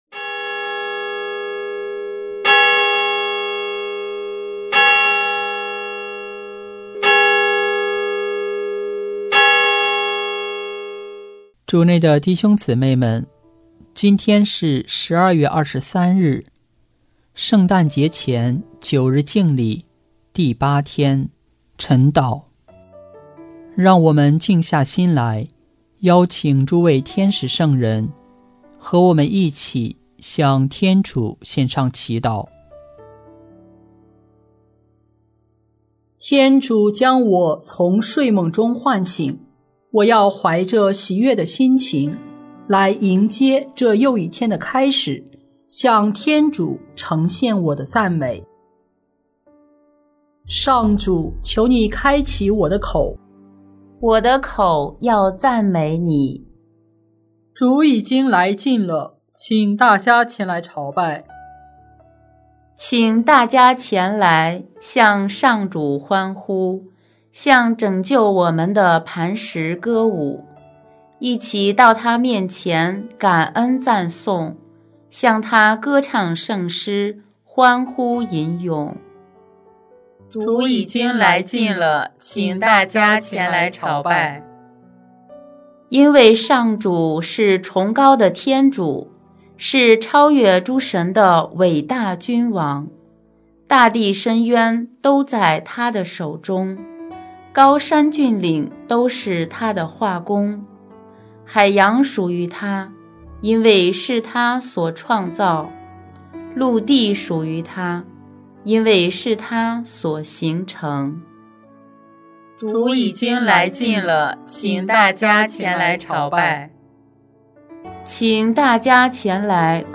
【每日礼赞】|12月23日将临期第四周星期二晨祷